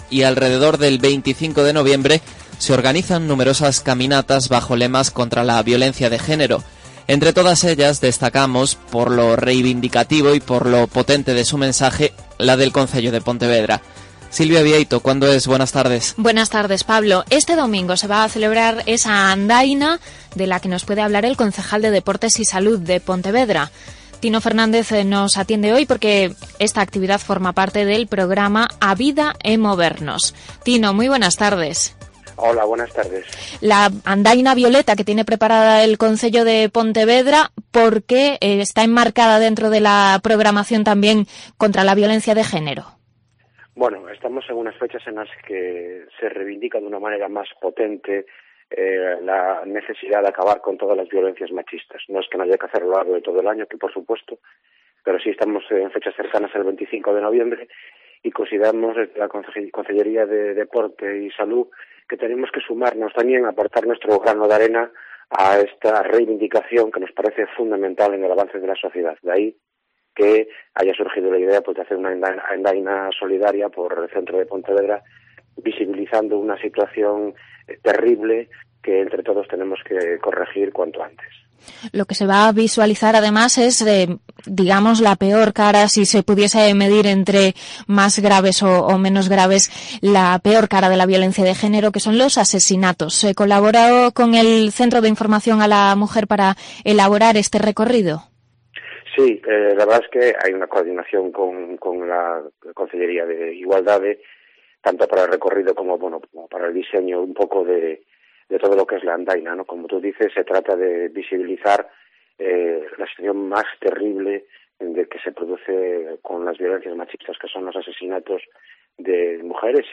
Entrevista al concejal de Deporte y Salud de Pontevedra sobre 'A vida é movernos'